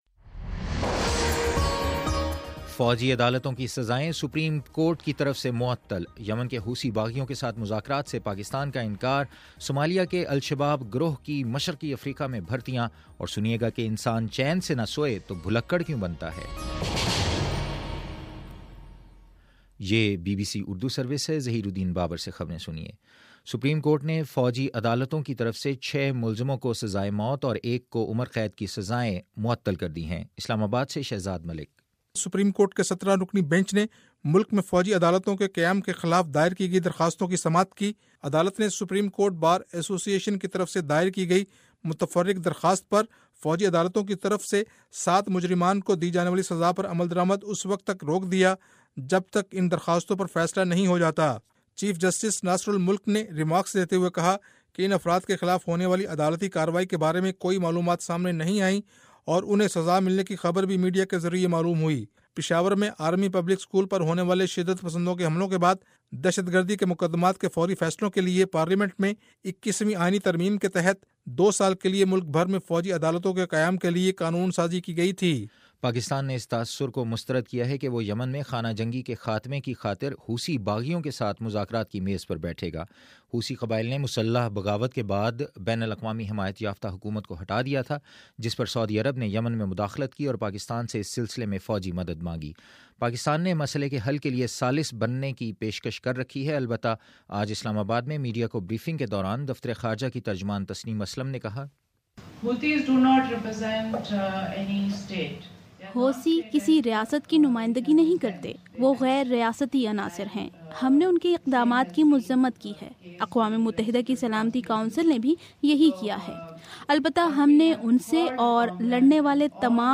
اپریل 16: شام چھ بجے کا نیوز بُلیٹن